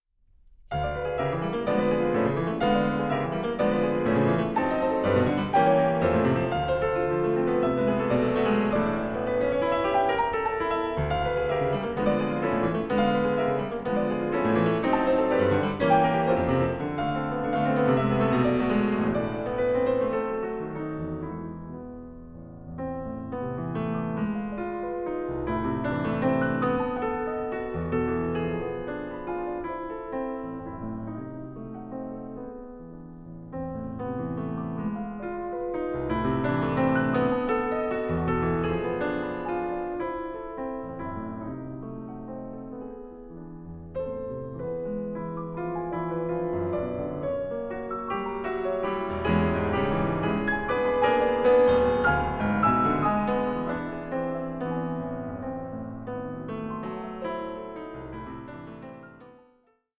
piano
By turns placid, sparse, restive and impassioned